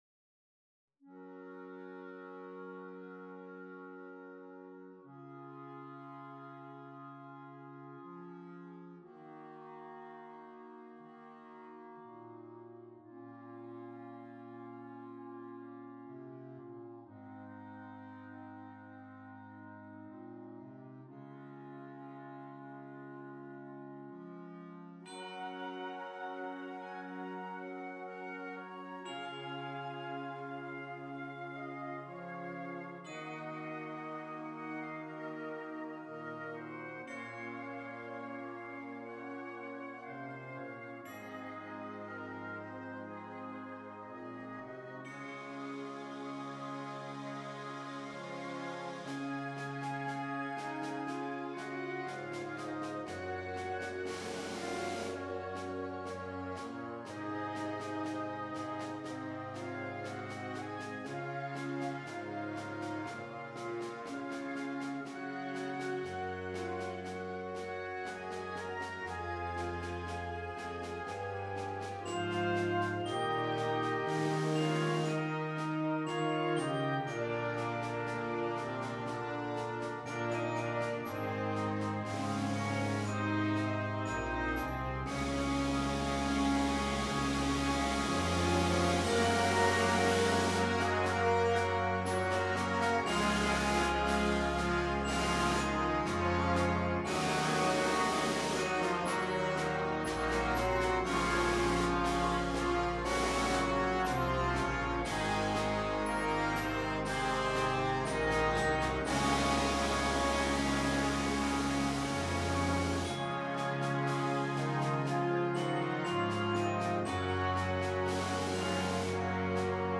A sorrowful sound began to emerge with a glitter of hope.